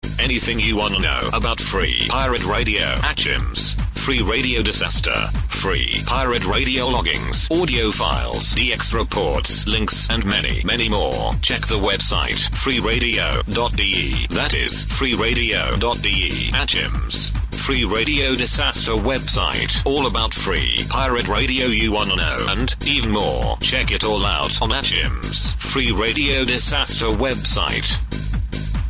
Promo for